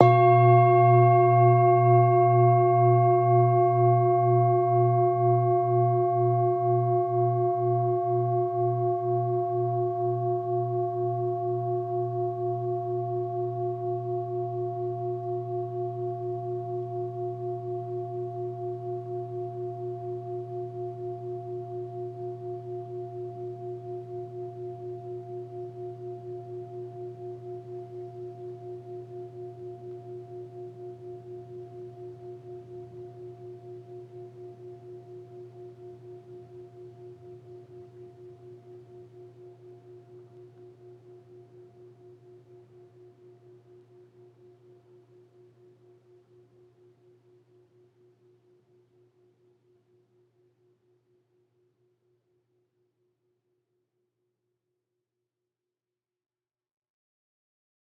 jan-bowl3-medium-A#1-mf.wav